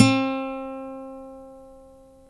Index of /90_sSampleCDs/Roland L-CD701/GTR_Nylon String/GTR_Nylon Chorus
GTR NYLON20C.wav